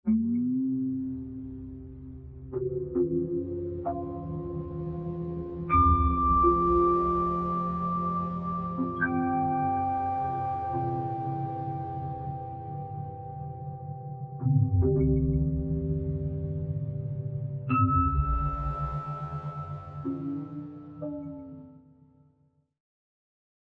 Figure 5. Analysis with frequencies smoothed 10+100 frames
110 frames smoothing of frequency begins to sound like vari-speed delay in the system, like a clean version of wow and flutter from a tape deck.